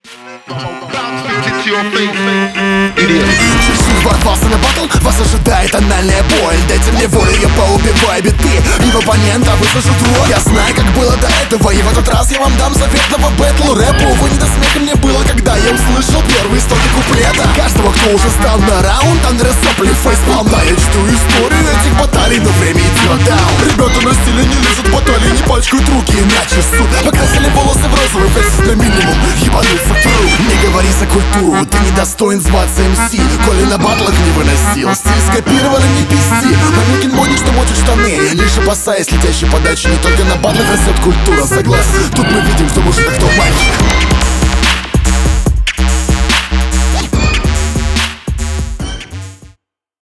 Читаешь вполне динамично, но выпады в сторону астрактных оппонентов очень слабы по своей идейности.